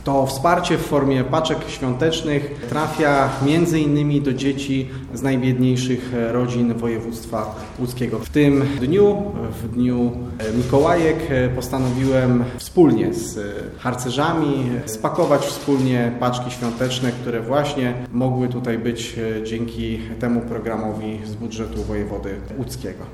Jak mówi wicewojewoda łódzki, Marcin Buchali- to upominek dla tych, którzy najbardziej tego potrzebują.